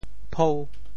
潮州府城POJ phôu